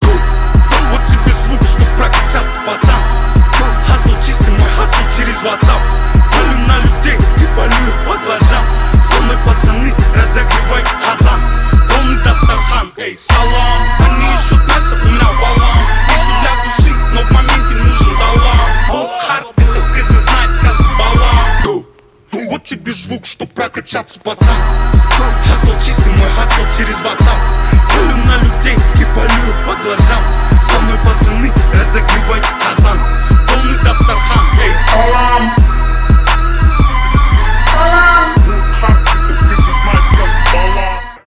Категория: Зарубежные рингтоныТанцевальные рингтоны